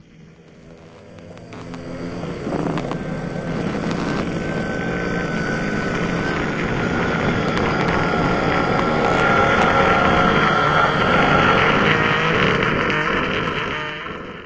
sounds / mob / endermen / stare.ogg